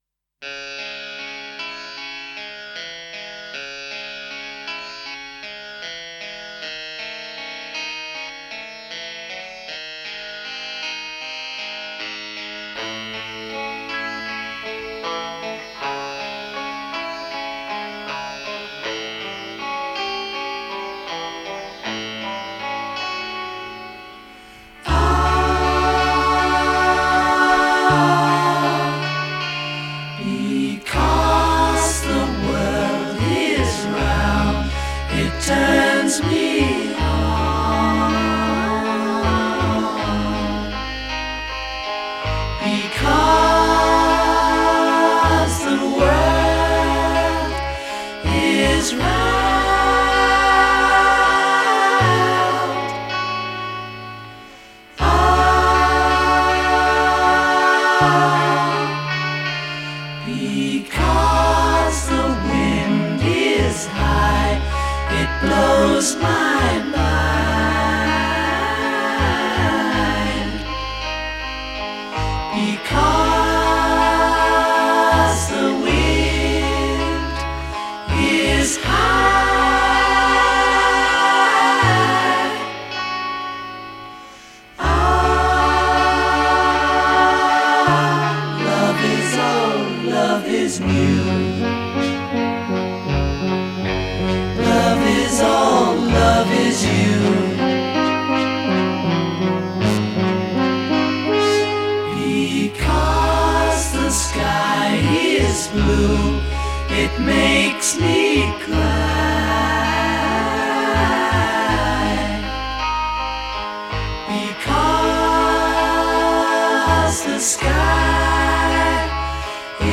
ELS 4 CANTAVEN I TOCAVEN ( A la vegada, no és fàcil )
Fixeu-vos bé en el joc de veus.